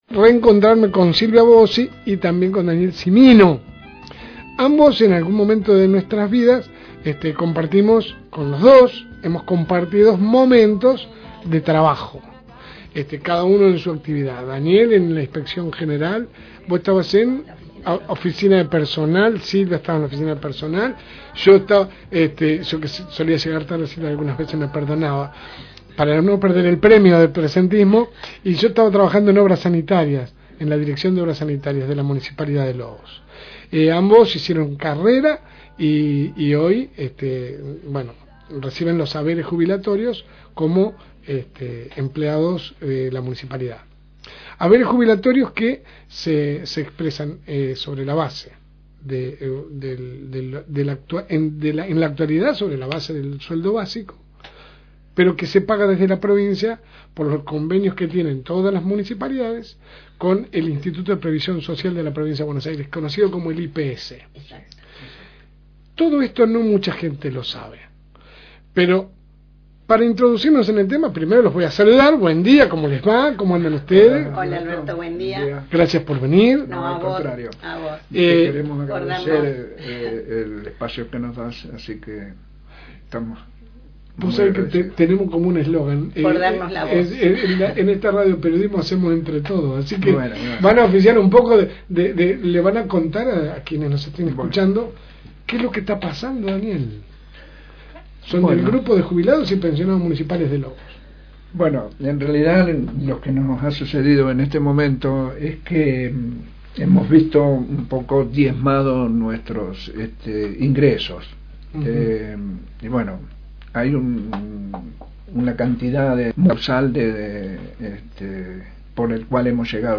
La situación económica de nuestro país golpea principalmente a las personas que trabajaron e hicieron sus aportes toda su vida y hoy se encuentran cobrando por debajo de los niveles de inflación. La historia se vuelve a repetir y les dejamos para que escuchen en voz de los protagonistas la situación de los y las jubiladas que pasaron por la administración pública de nuestra ciudad.